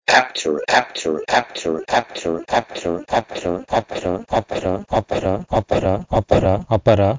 PIE-h2eptero-to-Sanskrit-apara.mp3